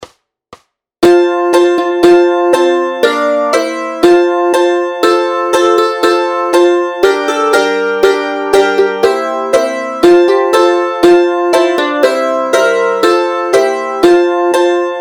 Formát Mandolínové album
Hudební žánr Lidovky